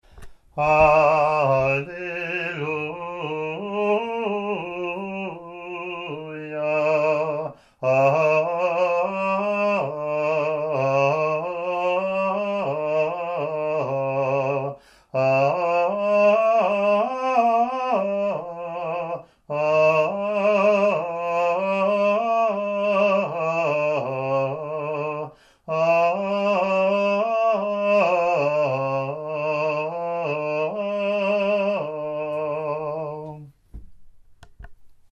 Alleluia Acclamation